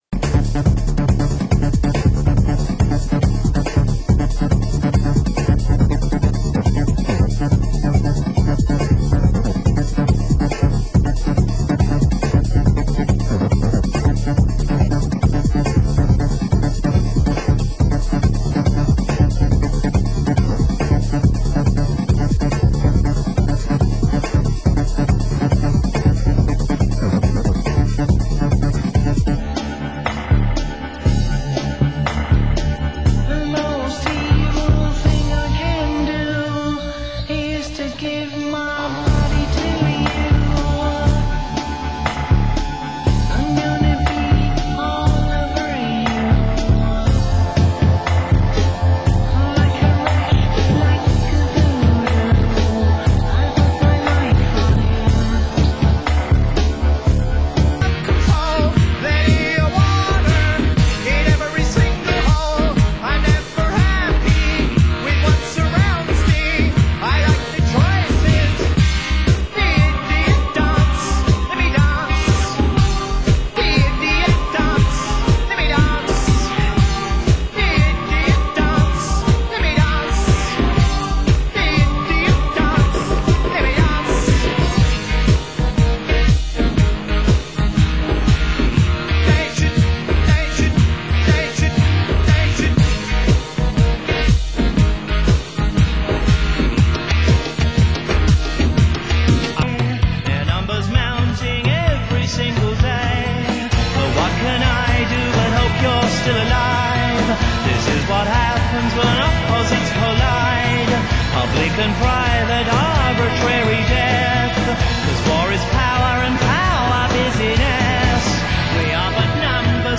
Genre: Progressive
Genre: UK Techno